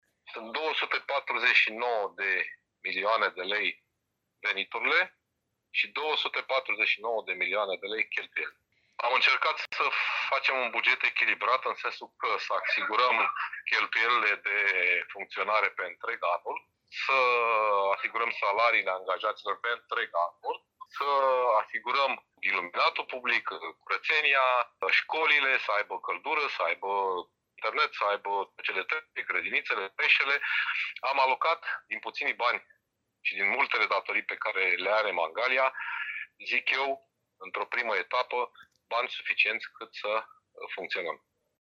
Viceprimarul municipiului Mangalia, Paul Foleanu: